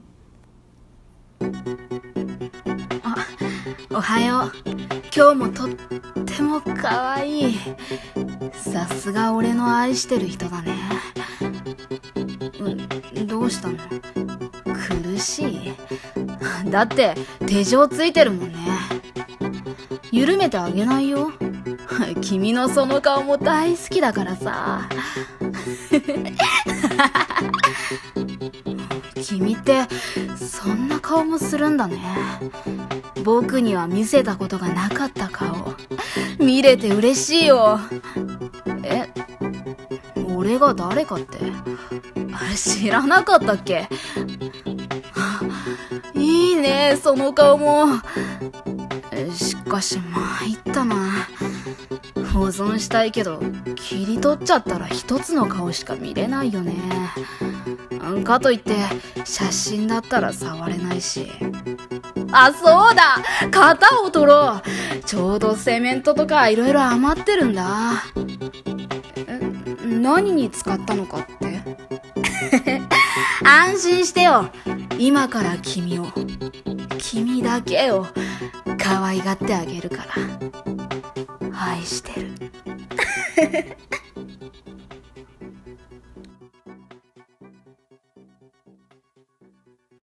【声劇台本】愛してあげる